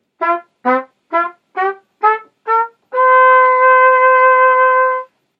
printed mouthpiece (attached to the trumpet) sounds compared to the